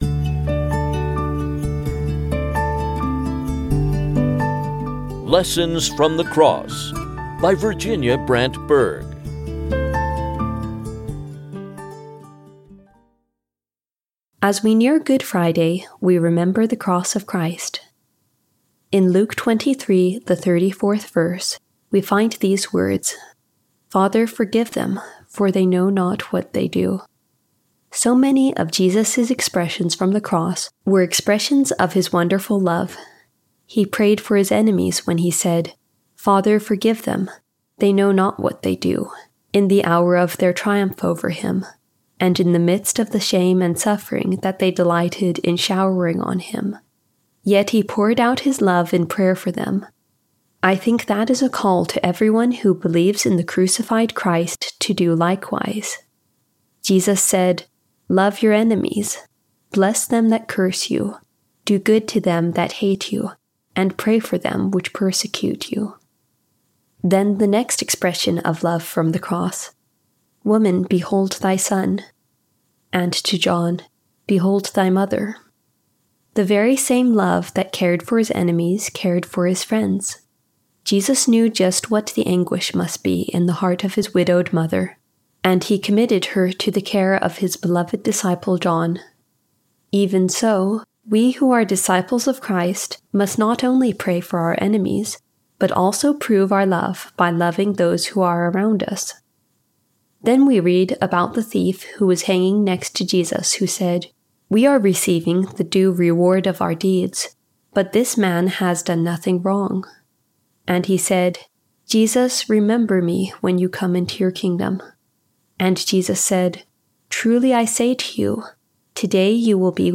From a transcript of a Meditation Moments broadcast, adapted.